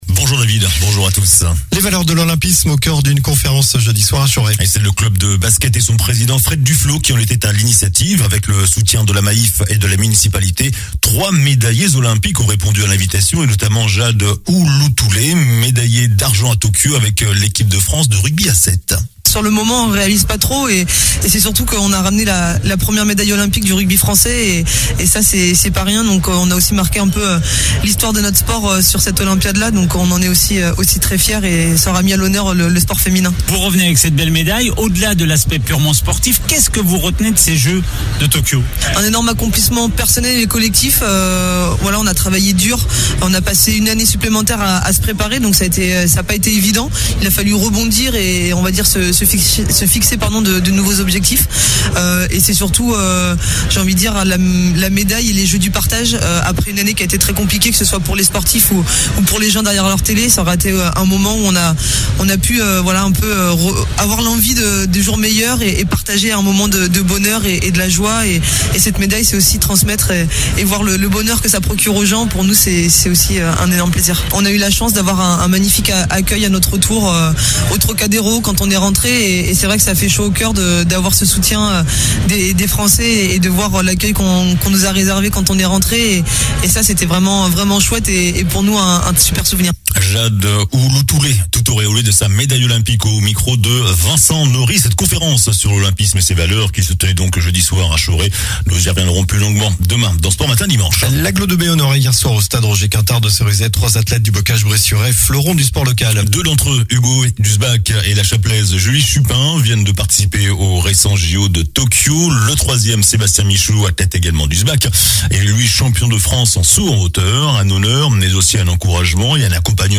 JOURNAL DU SAMEDI 25 SEPTEMBRE